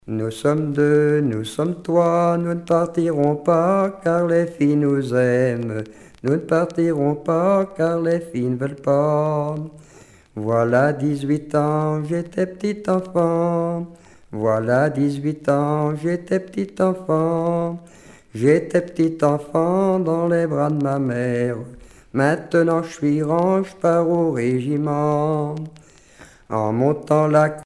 circonstance : conscription
Genre strophique
Pièce musicale éditée